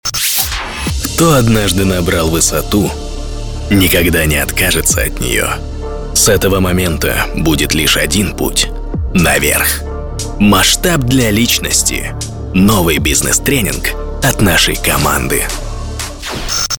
Низкий голос - солидная реклама
Муж, Рекламный ролик/Зрелый